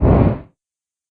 fire_impact_short.ogg